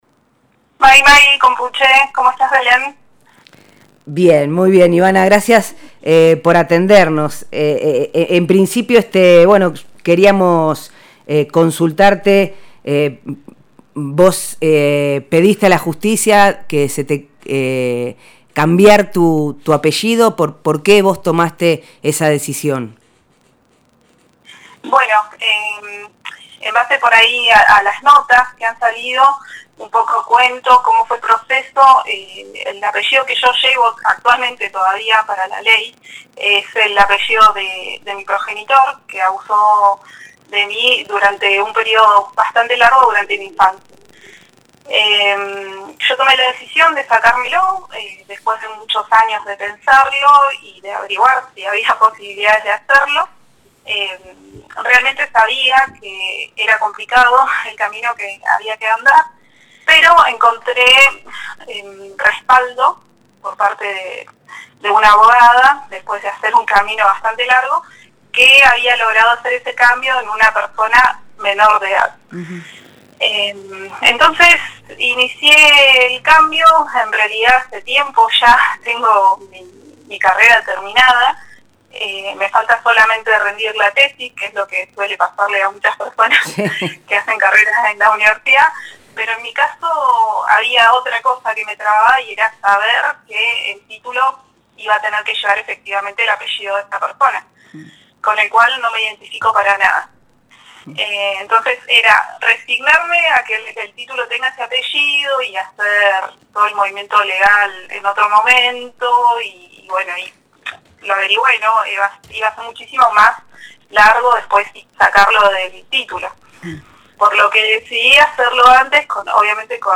En diálogo con Nosotres les Otres (jueves 18 a 21 hs fm 94.5)